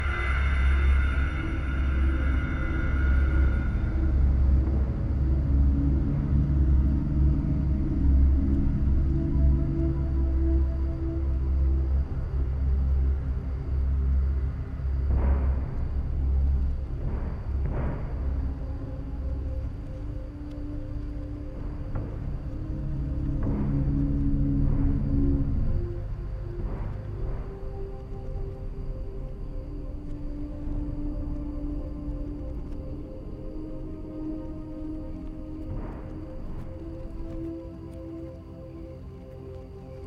When Hawk arrives at Glastonbury Grove, some backwards sounds can be heard behind the music. When played forward, it sounds like someone banging on a sheet of metal or a metal door.
Glastonbury-Grove-sounds-reversed.mp3